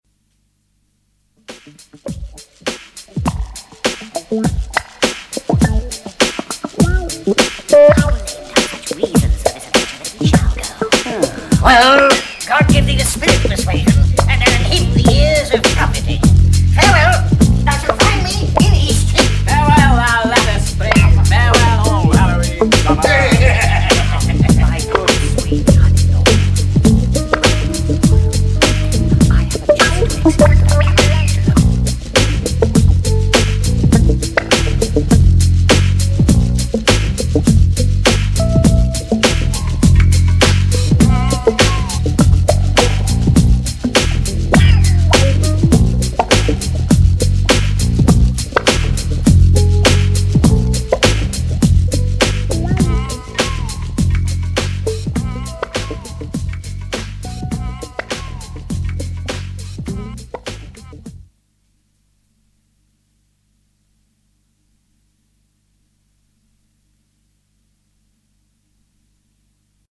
keyboards
is een dansvloer kraker van de eerste orde.